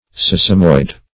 Sesamoid \Ses"a*moid\, n. (Anat.)